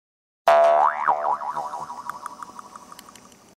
Roblox Gravity Coil Sound Effect